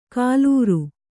♪ kālūru